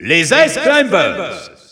Announcer pronouncing Ice Climbers in French PAL in victory screen.
Ice_Climbers_French_EU_Alt_Announcer_SSBU.wav